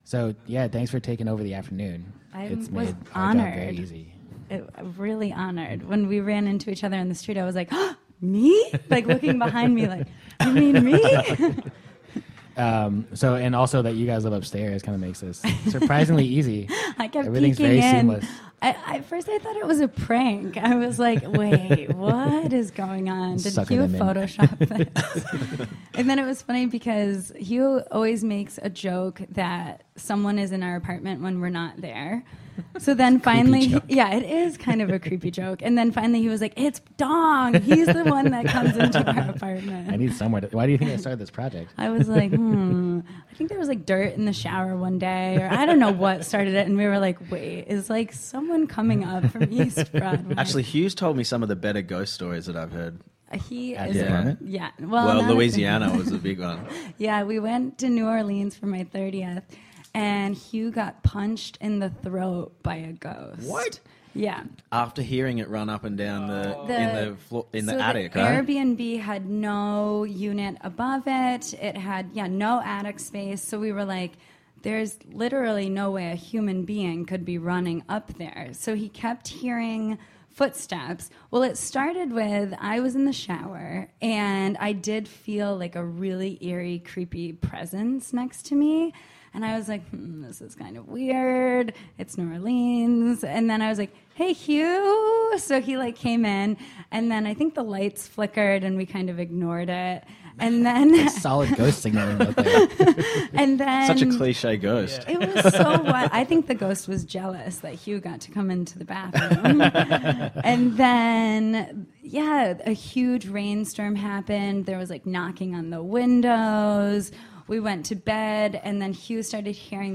Food Radio is a radio station started by Food New York . For season one, Office Hours , we took over a storefront in Chinatown and interviewed over 50 artists, designers, chefs, architects, entrepreneurs, and one politician, all to find out how they managed to make money doing what they love.
All of the interviews were recorded live.